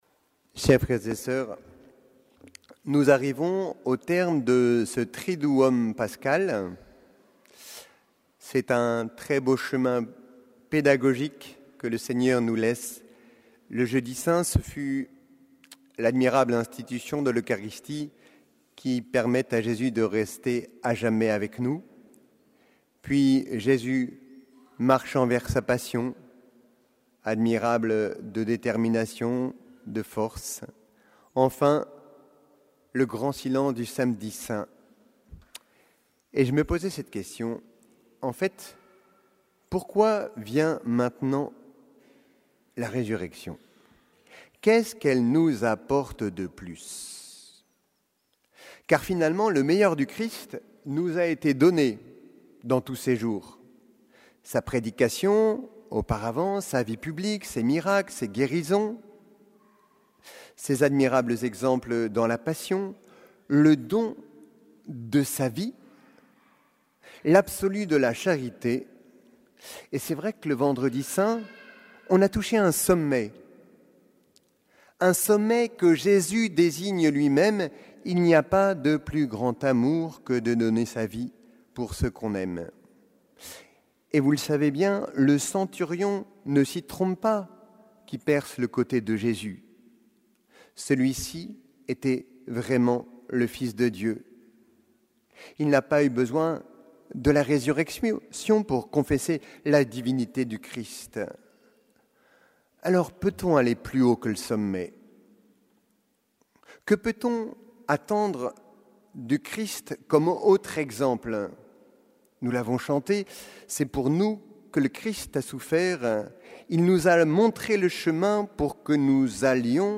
Homélie du dimanche de Pâques